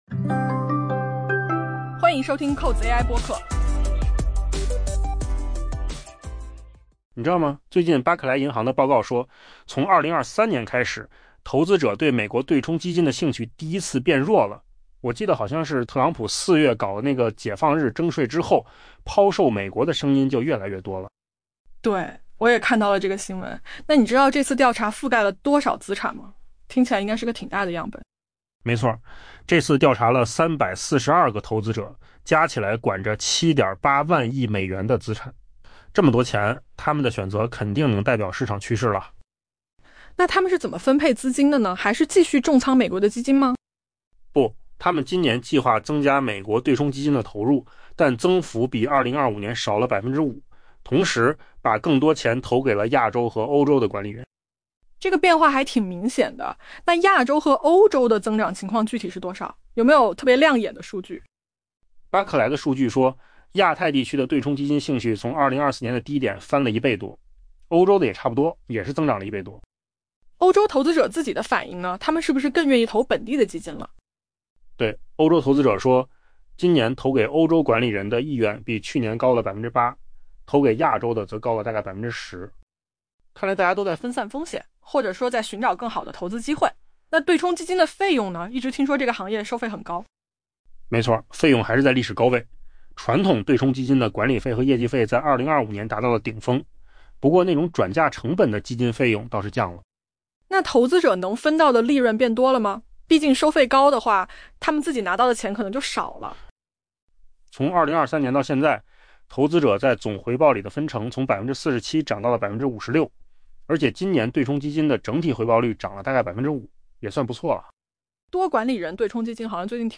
AI播客：换个方式听新闻 下载mp3 音频由扣子空间生成 巴克莱银行表示， 自2023年以来，投资者对美国对冲基金的兴趣首次出现减弱 。